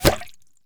bullet_impact_water_01.wav